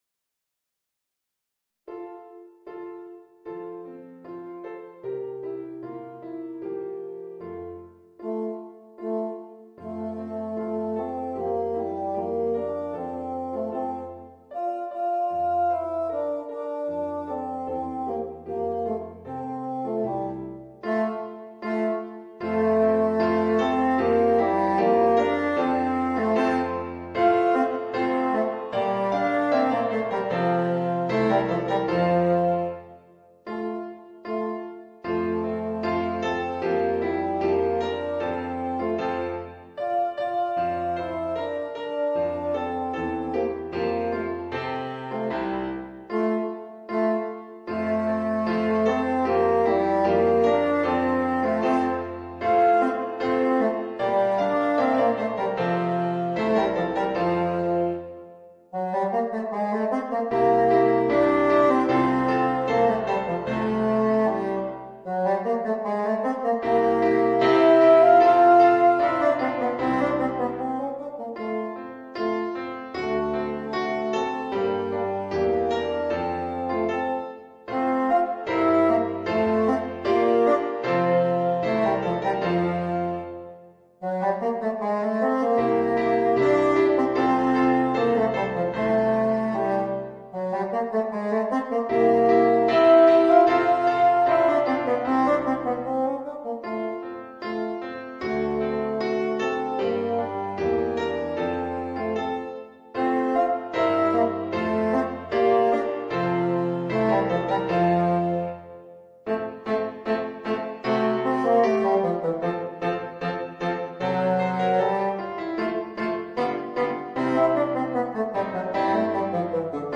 Bassoon and Piano